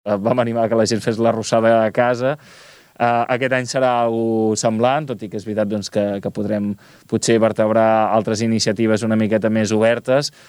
Ara bé, a conseqüència de l’actual pandèmia, la trobada de Sant Ponç com es feia anys enrere no es podrà realitzar, com ja va passar el 2020. El regidor de festes, Pau Megias, ha explicat quina previsió tenen a dia d’avui.